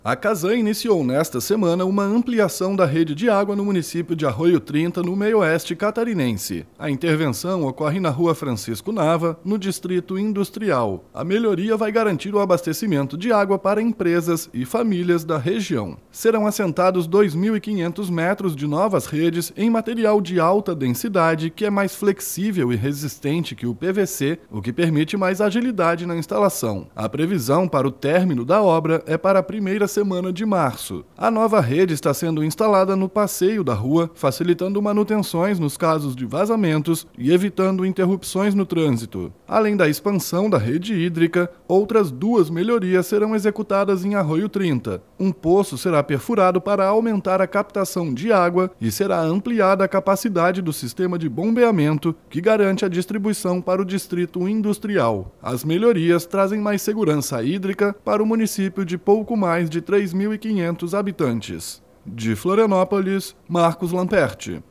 BOLETIM – Casan amplia redes de água no interior de Arroio Trinta